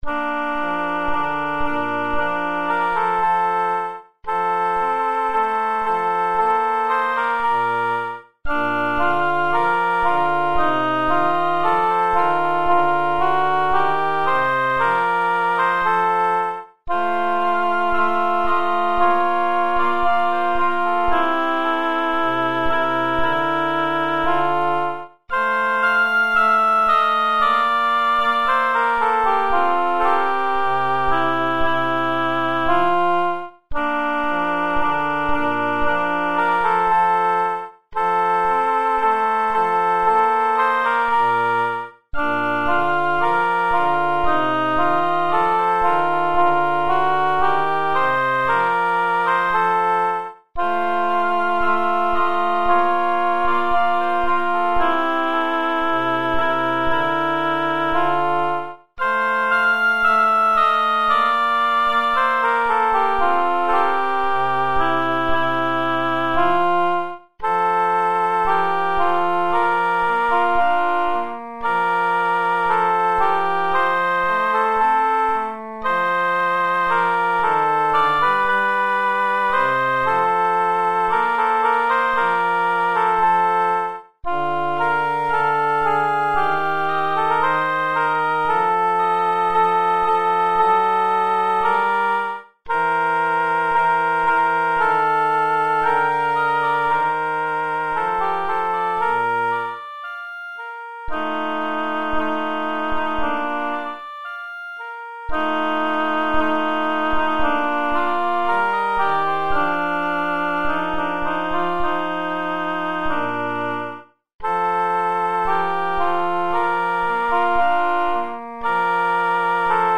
piu_non_si_trovano_alti.mp3